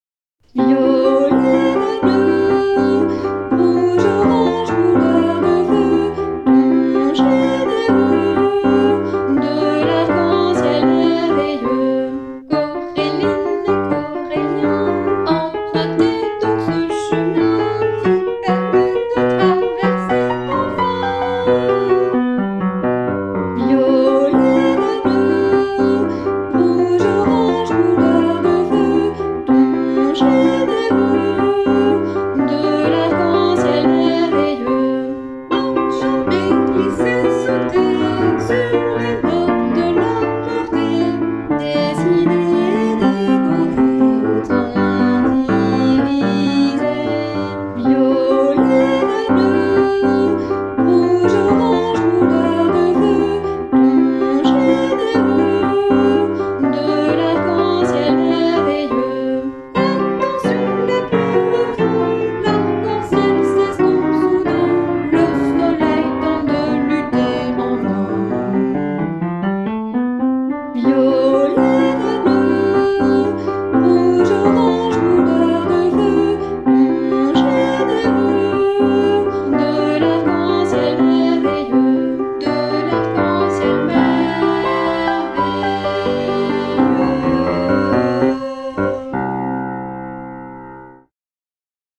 un conte musical pour enfants